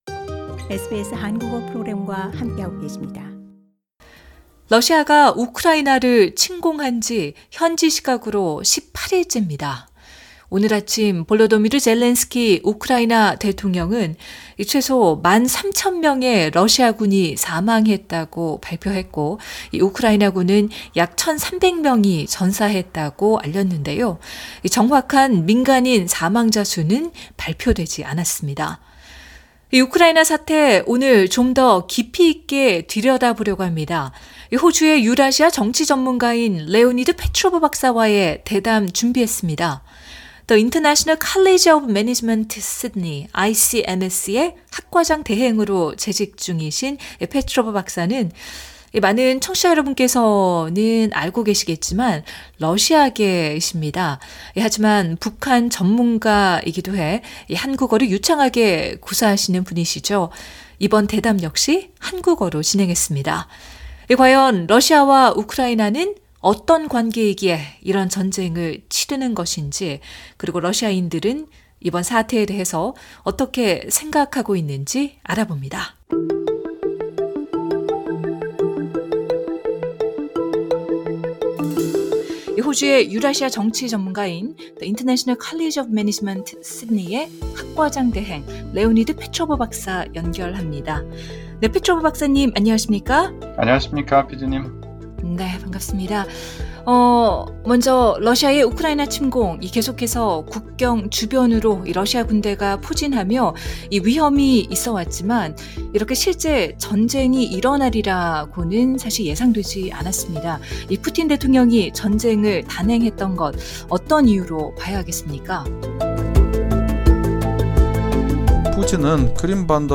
대담